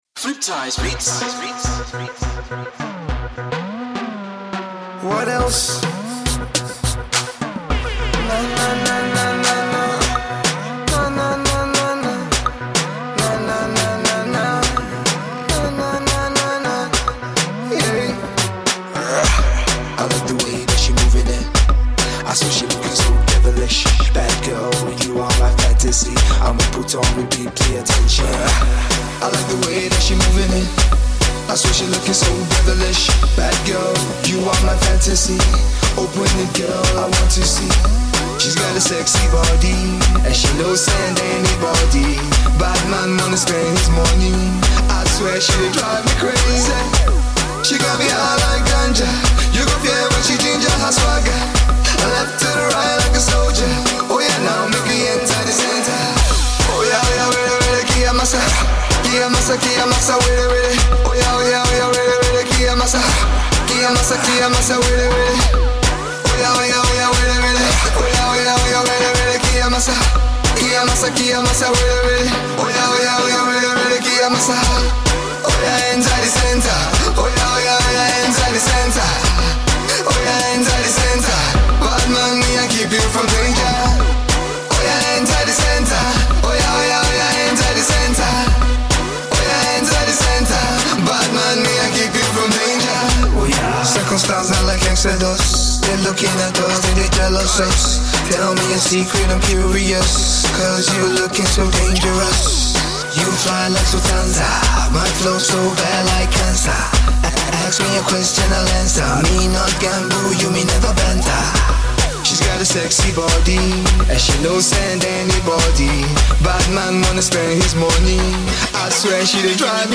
Pop/Rap
the smooth mid-tempo dance musical backdrop
captivating rap/vocal flow